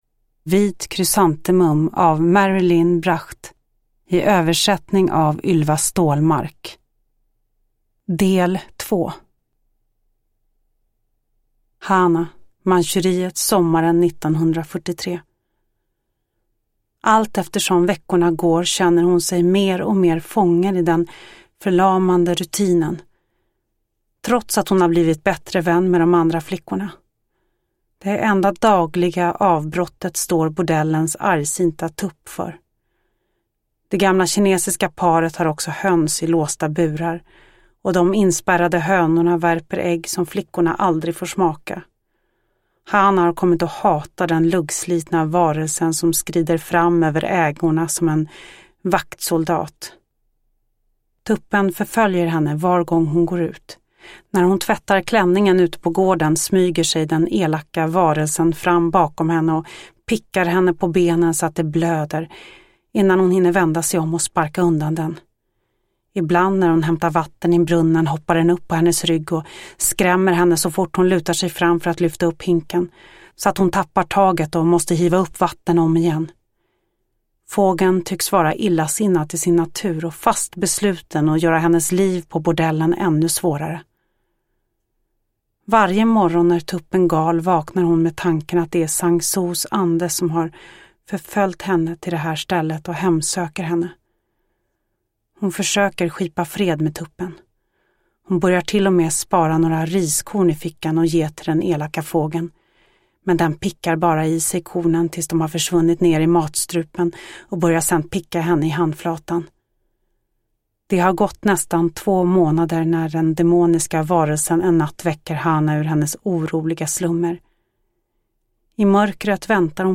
Vit Krysantemum, del 2 av 2 – Ljudbok
Uppläsare: Lo Kauppi